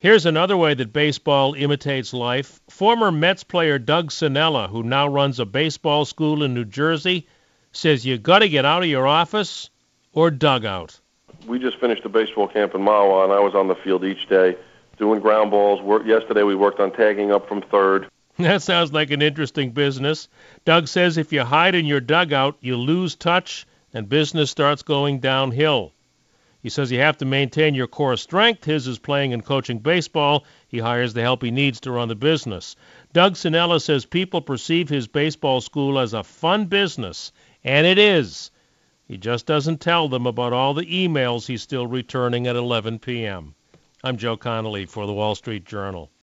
Special thanks to WCBS Newsradio 880.